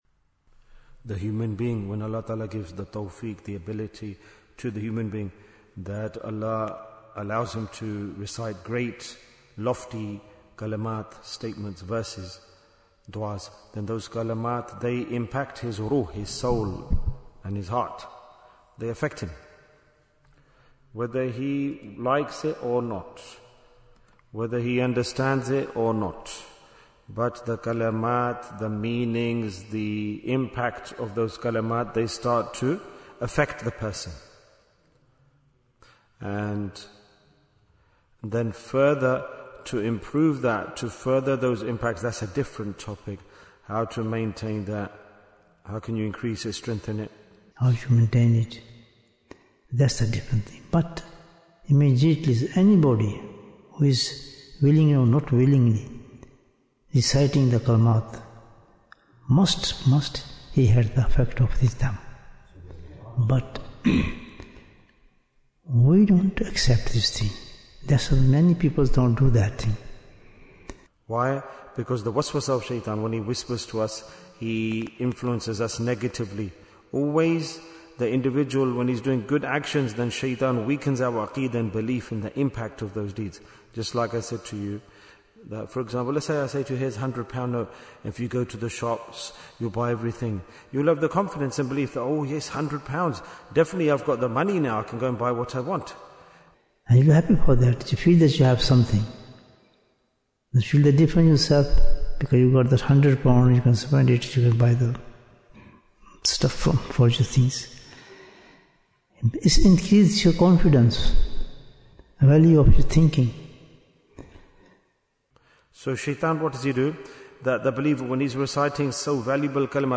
Why is Tazkiyyah Important? - Part 26 Bayan, 48 minutes5th April, 2026